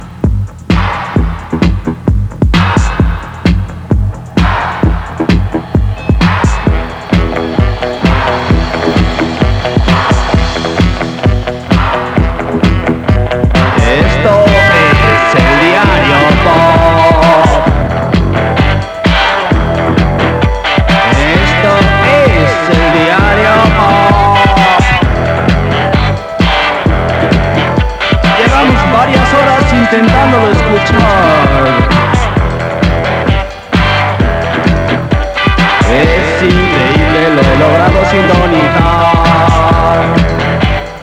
Indicatiu cantat del programa